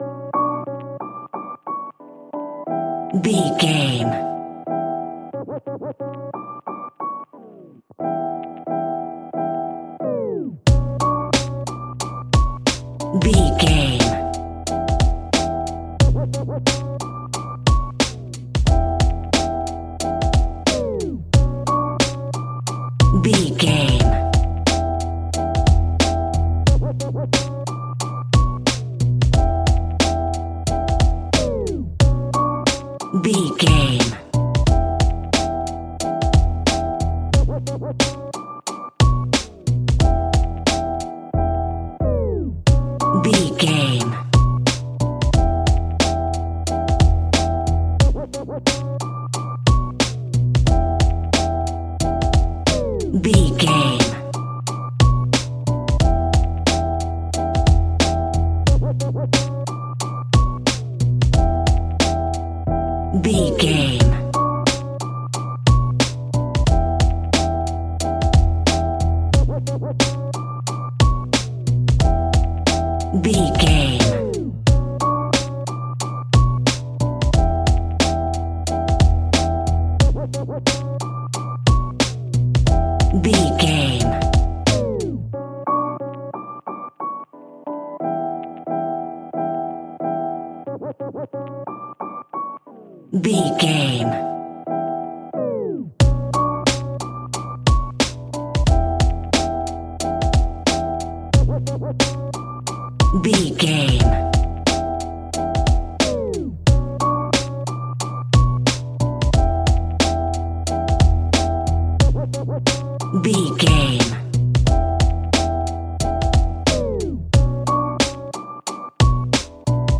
West Coast Urban Alt.
Aeolian/Minor
chilled
laid back
groove
hip hop drums
hip hop synths
piano
hip hop pads